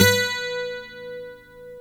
GTR 12 STR0P.wav